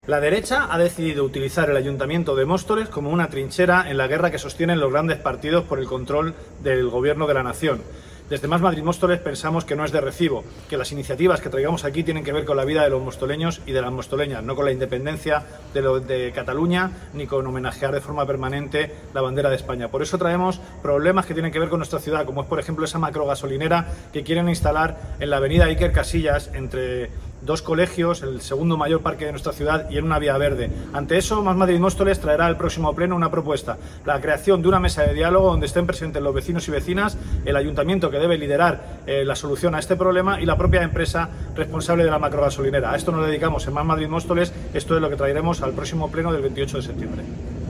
declaraciones-emilio-delgado-gasolinera.mp3